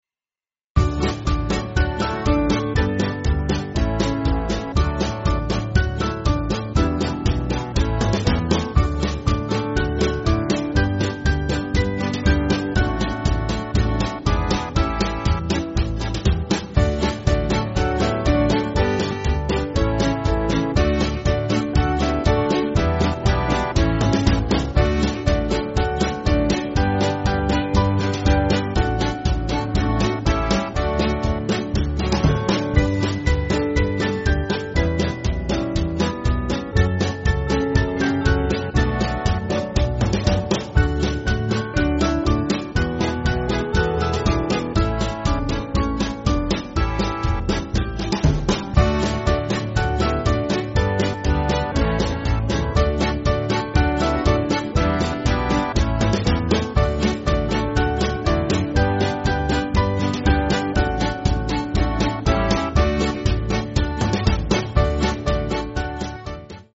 Yiddish folk tune
Small Band
Jewish Feel